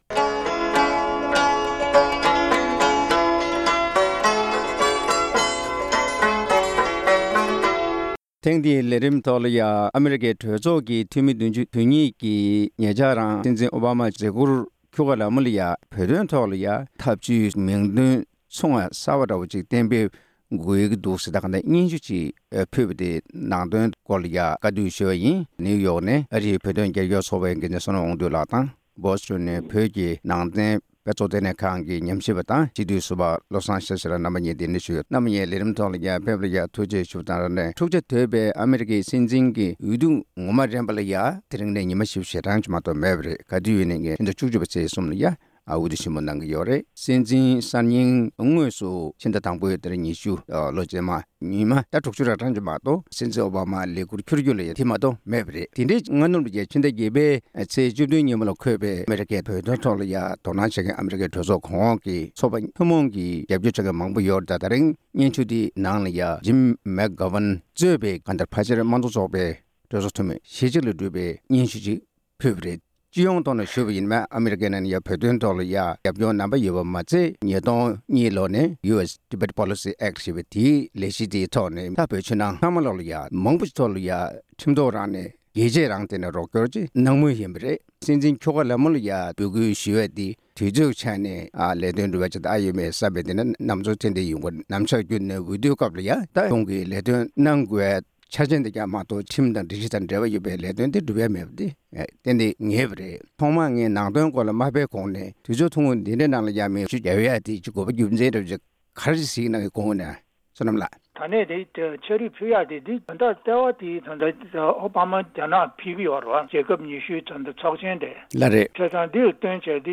གླེང་མོལ་ཞུས་པ་ཞིག་གསན་རོགས་ཞུ༎